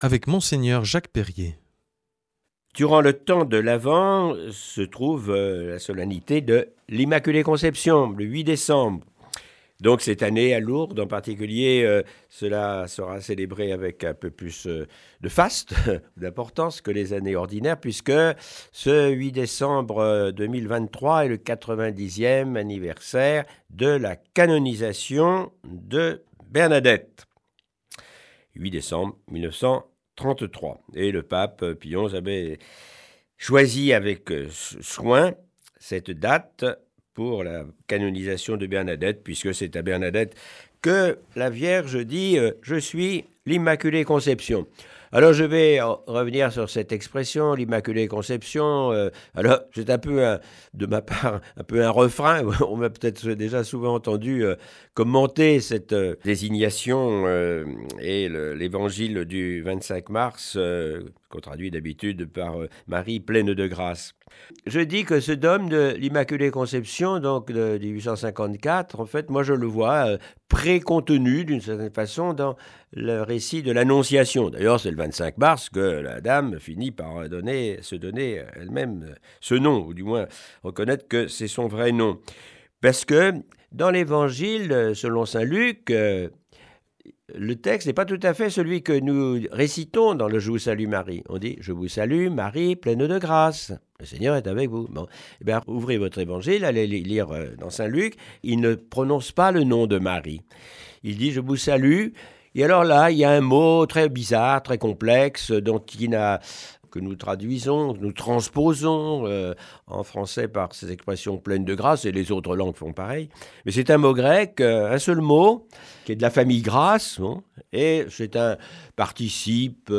Aujourd’hui avec Mgr Jacques Perrier, évèque émérite de Tarbes et Lourdes.